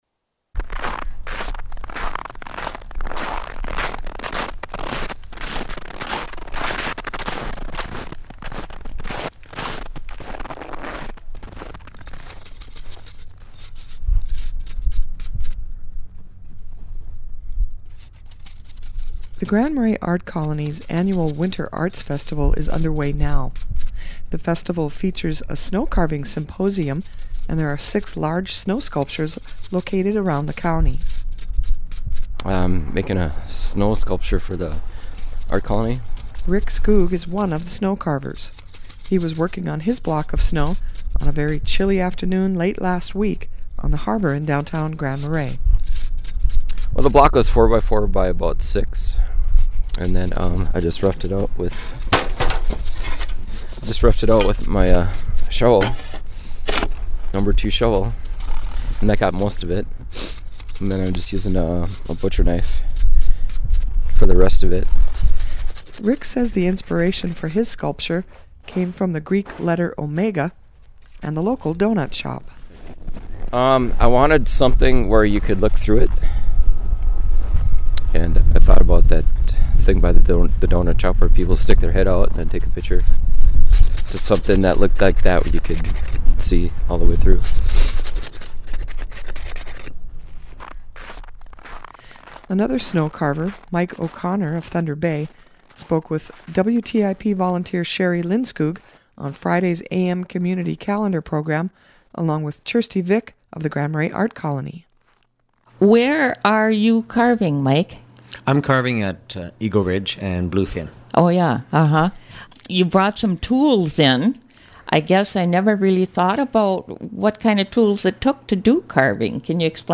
(Click on the audio mp3 above to hear from two of the snow carvers in this year's symposium.)